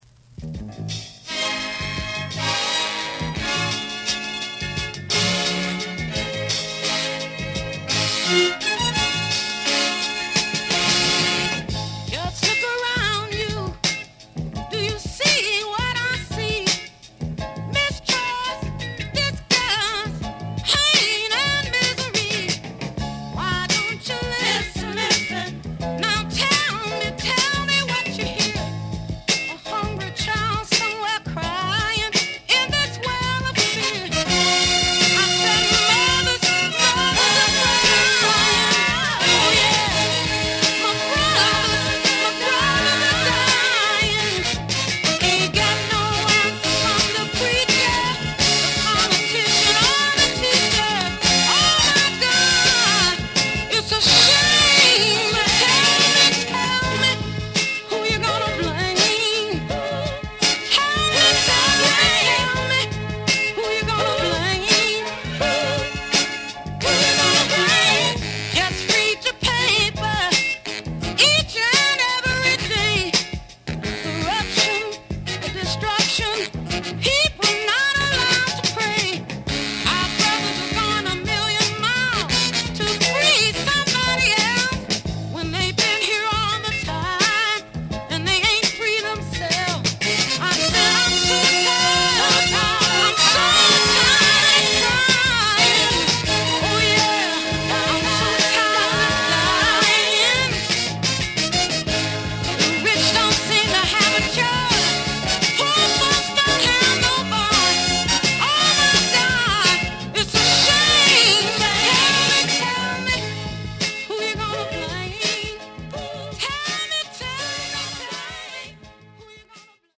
Sweet soul